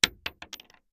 Bullet Shell Sounds
pistol_wood_6.ogg